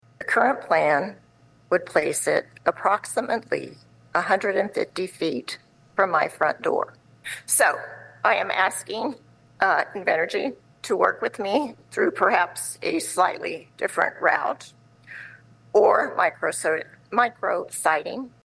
The 800 mile Grain Belt Express aimed to carry wind power from Kansas eastward but drew rural backlash.  During a 2024 Meade County, Kansas Corporation Commission hearing